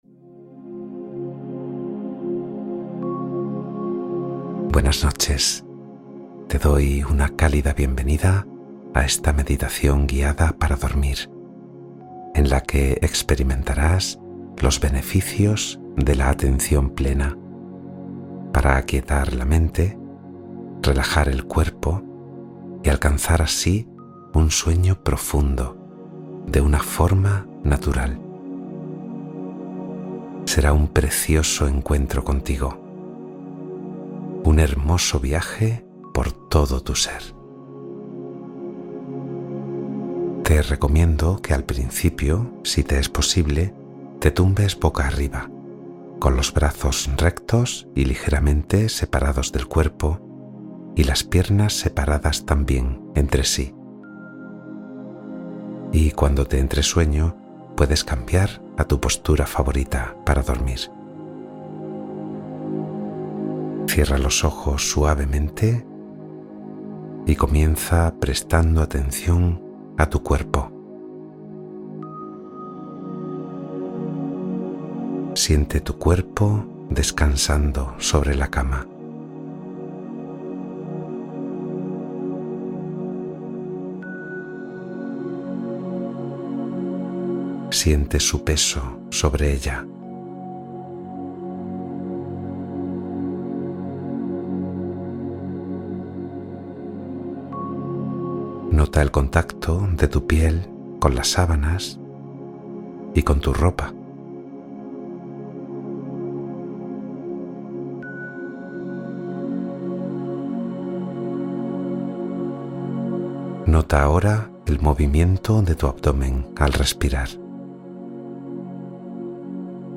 Duerme Rápido con esta Meditación Mindfulness para el Sueño